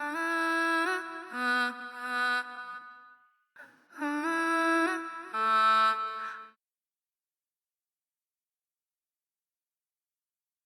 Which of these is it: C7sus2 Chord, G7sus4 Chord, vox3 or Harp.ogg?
vox3